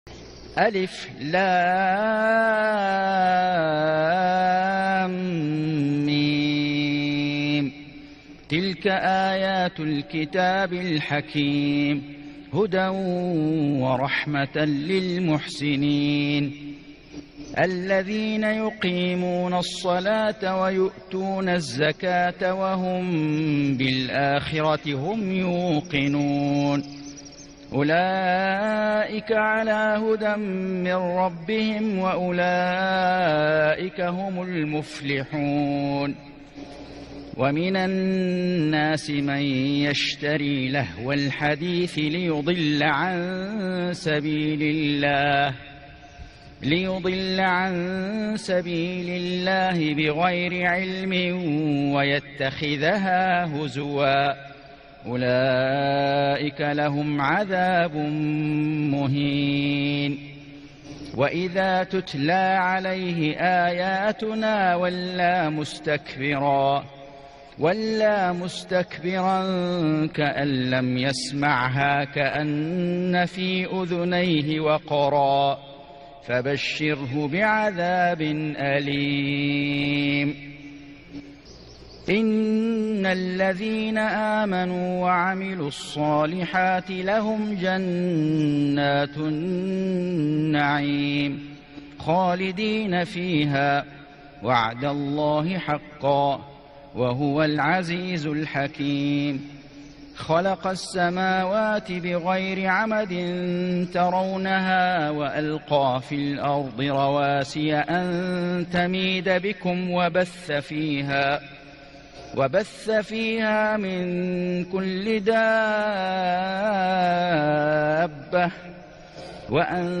سورة لقمان > السور المكتملة للشيخ فيصل غزاوي من الحرم المكي 🕋 > السور المكتملة 🕋 > المزيد - تلاوات الحرمين